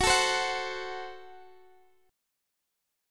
Listen to Gbdim strummed